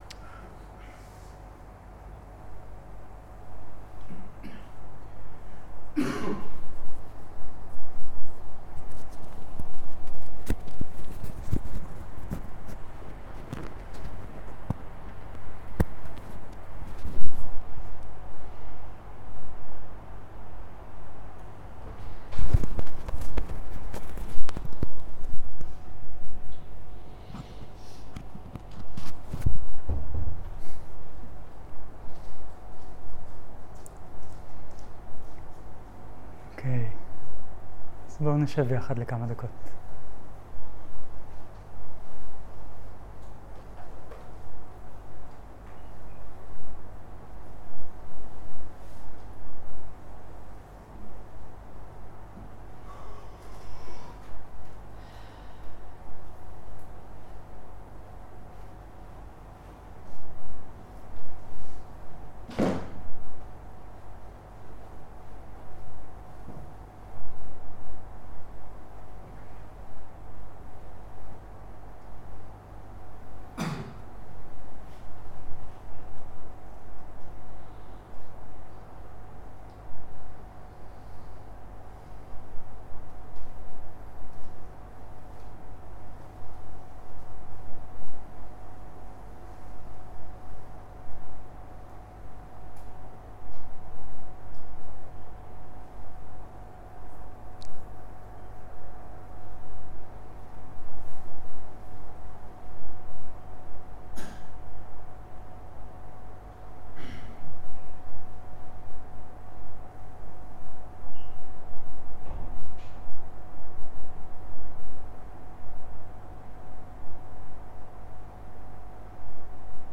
שיחת דהרמה - סמאדהי והתהוות העצמי
סוג ההקלטה: שיחות דהרמה